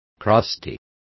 Also find out how costrosa is pronounced correctly.